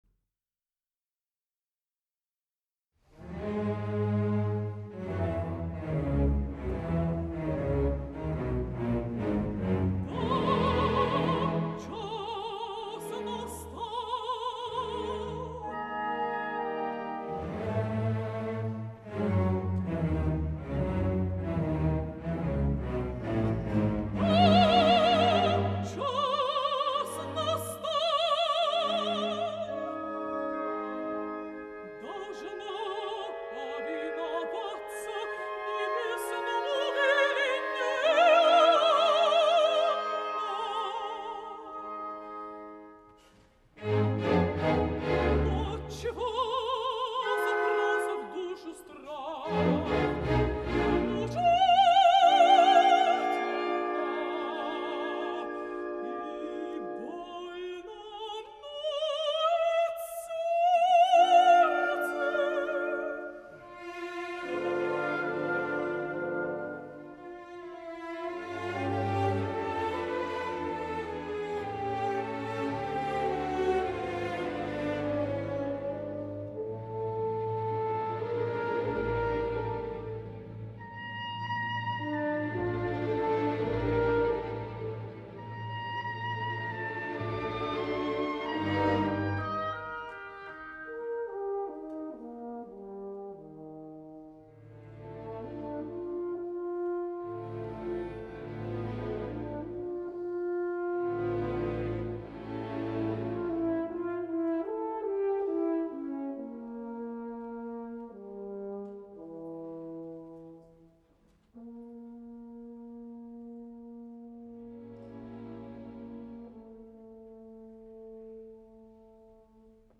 La magnífica i enlluernadora mezzosoprano letona
El passat 12 de juny de 2012 ambdós van oferir un concert a la sala Philharmonie im Münchner Gasteig, amb la Münchner Rundfunkorchester.
Vocalment Garanča està esplendorosa, res a dir, ans al contrari, i per a mi ens ofereix tres moments de veritable interès. les àries de La donzella d’Orleans de Txaikovski, la de Charles Gounod provinent de l’òpera La reina de Saba, i sobretot “L’amour est enfant de bohème” que era l’ària original d’entrada de Carmen, i que Bizet va substituir per la havanera provinent del “arreglito” de Iradier, molt més seductora.